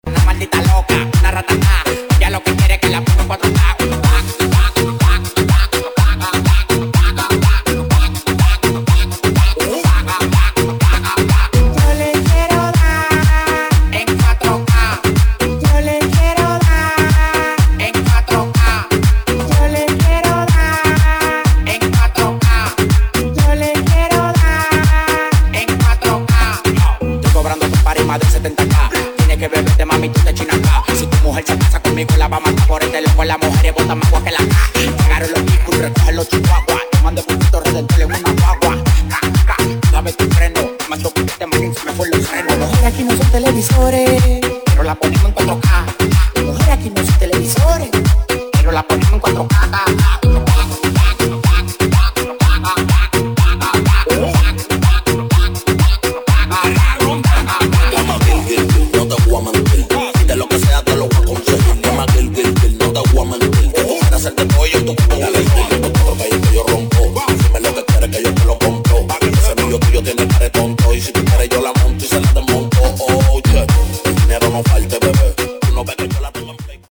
BPM: 124 Time